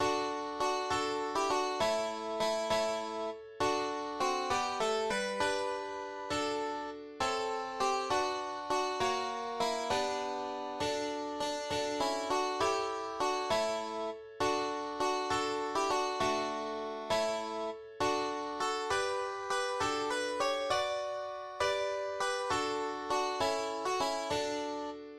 } \relative c' { \time 6/8 \tempo 4=100 \key d \major \clef "treble" \set Staff.midiInstrument="Harpsichord" fis4 fis8 a8. g16 fis8 | e4 e8 e4 r8 | fis4 fis8 g8 a8 b8 | b4. a4 r8 | a4 g8 fis4 fis8 | fis4 e8 d4.